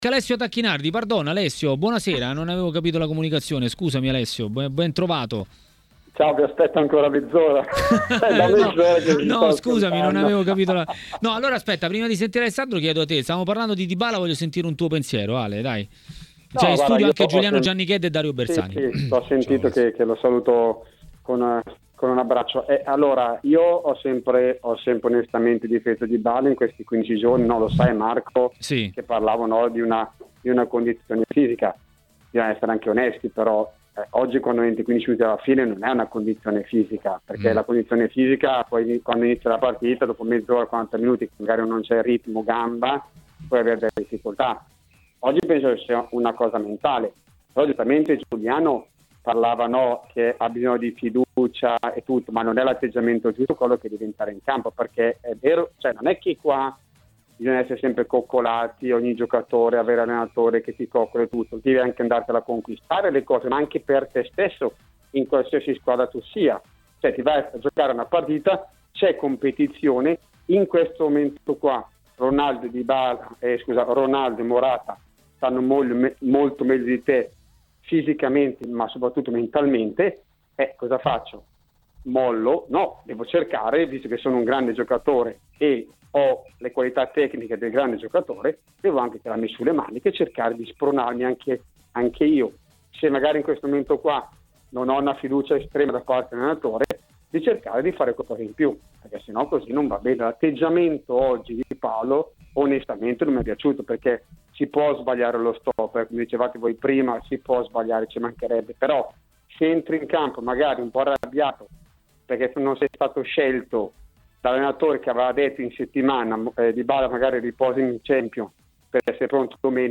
L'ex calciatore e opinionista tv Alessio Tacchinardi a TMW Radio, durante Maracanà Show, ha commentato le notizie di giornata.